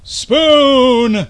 In 1995, Wired magazine's AOL forum located Elwood Edwards -- whose voice recorded the sound file "You've Got Mail" -- and had him record ten additional sound files.